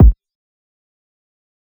Ain't No Time Kick.wav